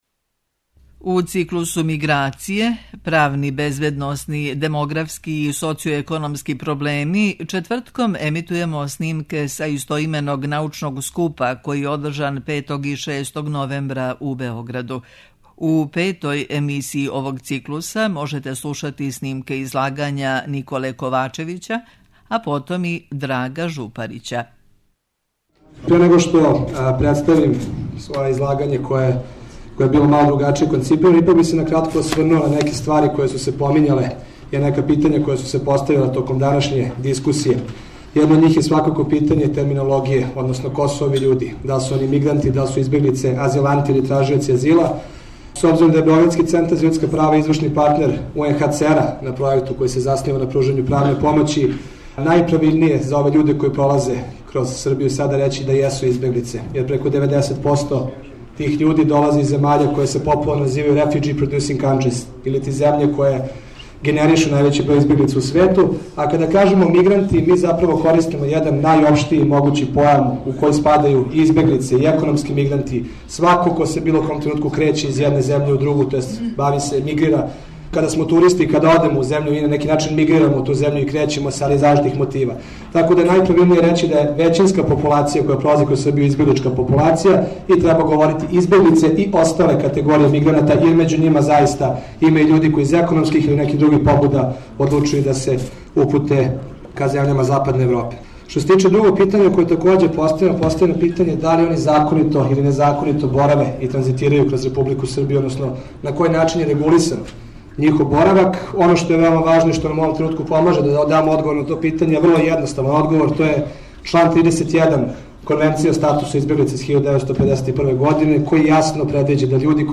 У циклусу МИГРАЦИЈЕ: ПРАВНИ, БЕЗБЕДНОСНИ, ДЕМОГРАФСКИ И СОЦИО-ЕКОНОМСКИ ПРОБЛЕМИ четвртком емитујемо снимке са истоименог научног скупа који је одржан 5. и 6. новембра у Хотелу Палас у Београду.